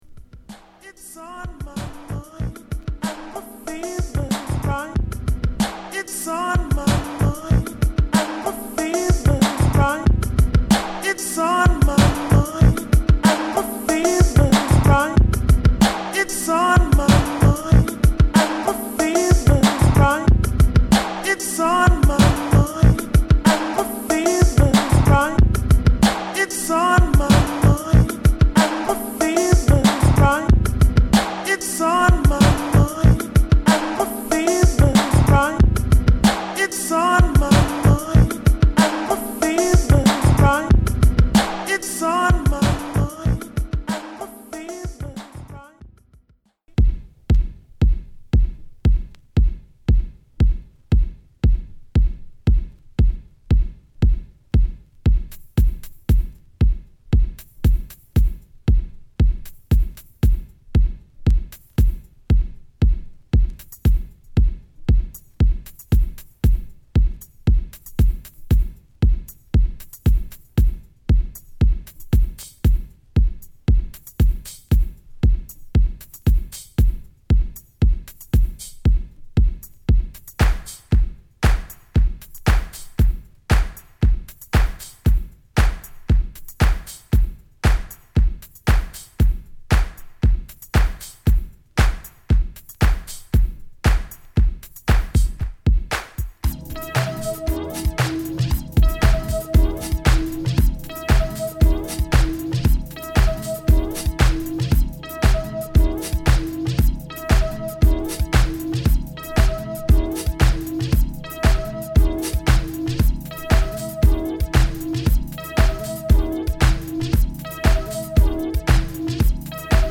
＊試聴はA1→A2→A3→A6→B2です。